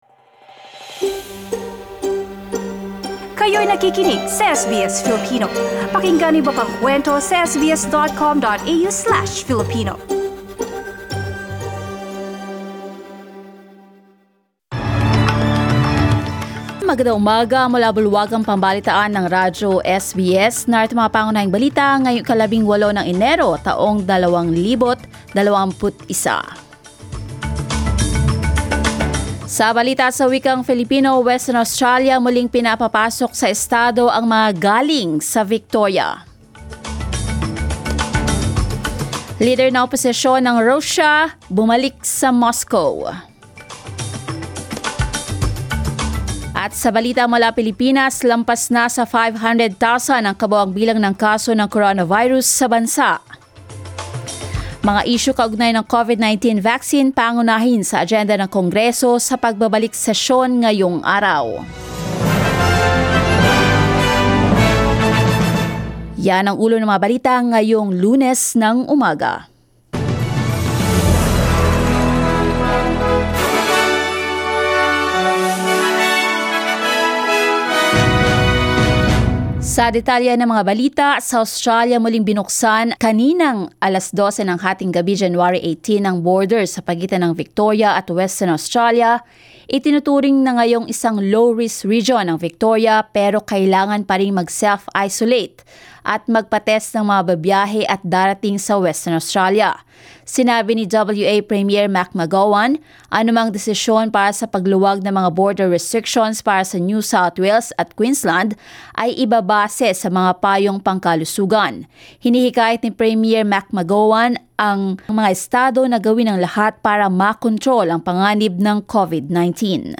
SBS News in Filipino, Monday 18 January